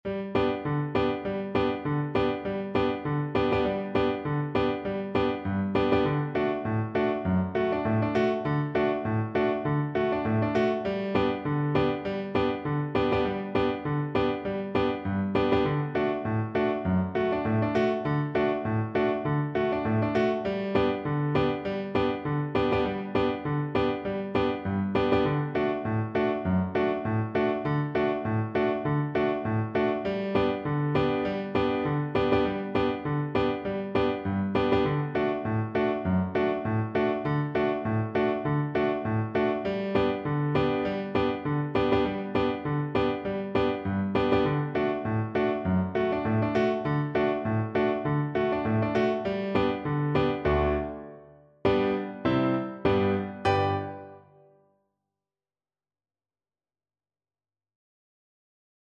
Violin
Traditional Music of unknown author.
G major (Sounding Pitch) (View more G major Music for Violin )
Two in a bar with a light swing =c.100